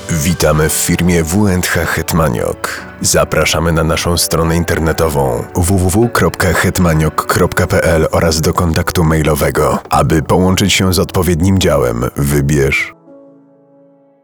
Głos o niskiej intonacji, z subtelnym ciepłem, które wprowadza słuchacza w spokojny, intymny nastrój. Jego niska tonacja nadaje każdemu słowu charakteru, a głębia brzmienia sprawia, że jest przyjemny w odbiorze.
Centralka telefoniczna: realizacja dla W&H Hetmaniok